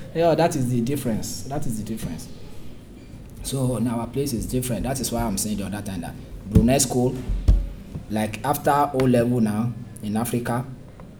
S3 = Nigerian male
One problem here is that the first syllable of attend and also the indefinite article a both seem to have a full vowel rather than a schwa. In addition, you attend a is said quite fast, and there is no clear stress on the second syllable of attend .